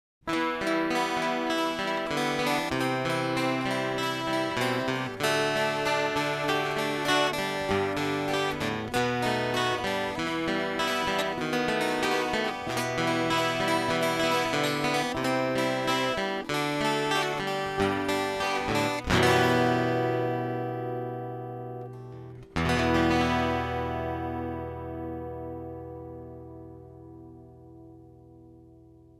オベは３バンドEQ、YEは４バンドEQなんで音作りは個人差がありますわ。
両方ともピエゾの音って感じっすねぇ。
でも、オベはやっぱ独特のピエゾ音って感じがします。